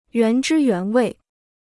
原汁原味 (yuán zhī yuán wèi) Free Chinese Dictionary